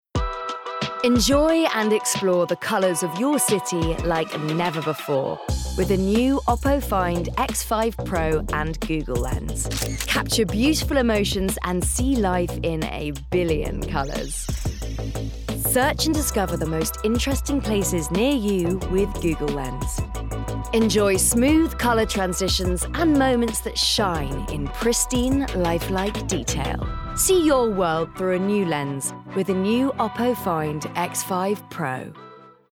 20/30's Neutral/London,
Assured/Engaging/Gravitas
Commercial Showreel